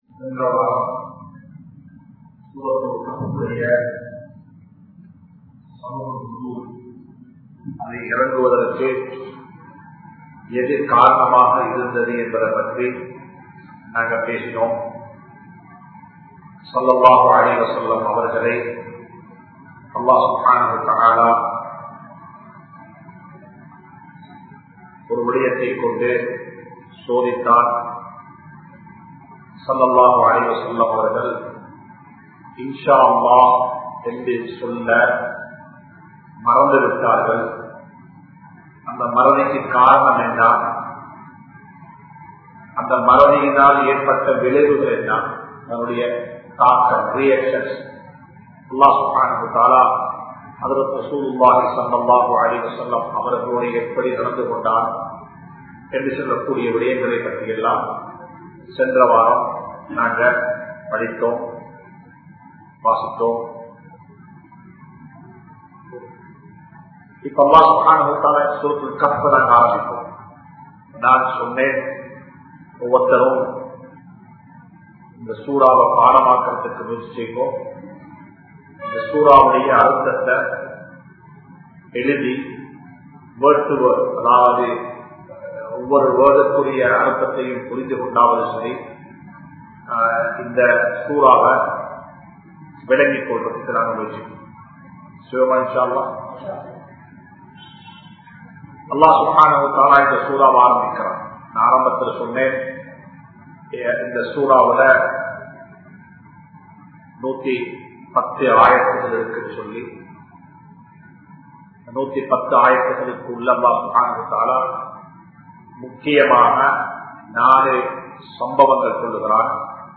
Soorathul Kahf Thafseer Part-03 (சூரத்துல் கஹ்ப் தப்ஸீர் பாகம்-03) | Audio Bayans | All Ceylon Muslim Youth Community | Addalaichenai
Majma Ul Khairah Jumua Masjith (Nimal Road)